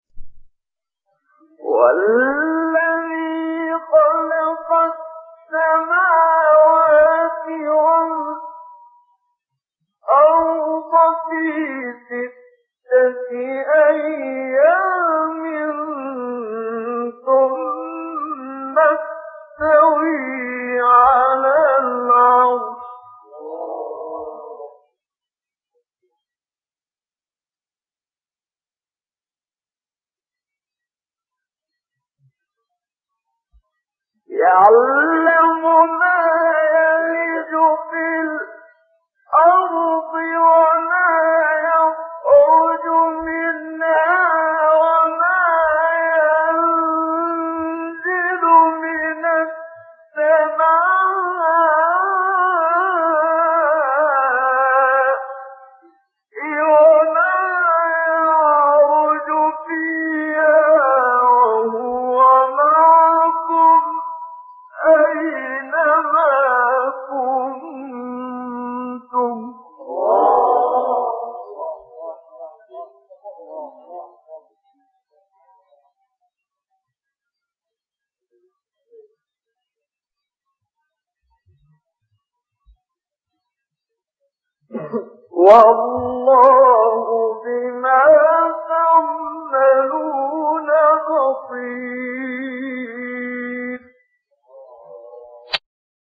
سایت-قرآن-کلام-نورانی-حجاز-منشاوی-2.mp3